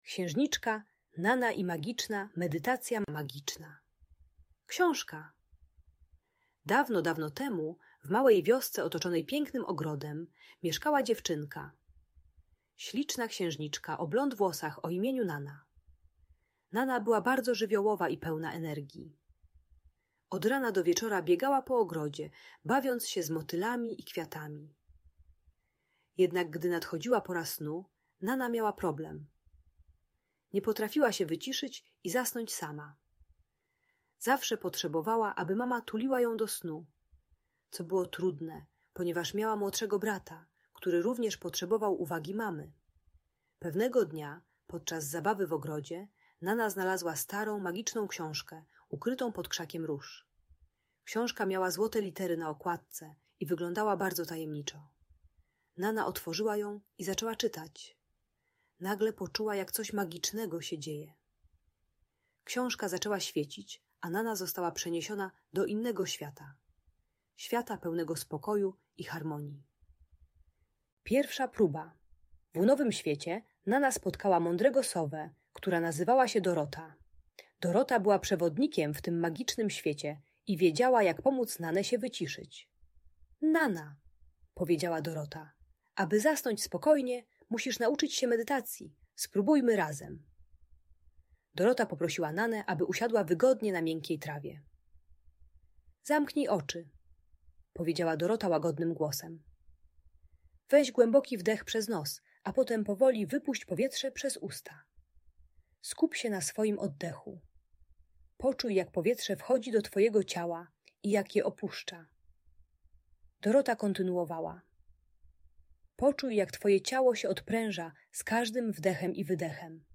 Księżniczka Nana i Magiczna Medytacja - story - Audiobajka